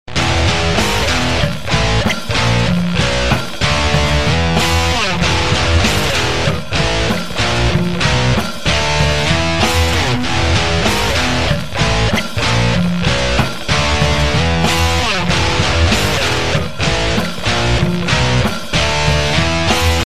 Rock Ringtones